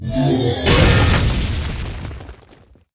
ram.ogg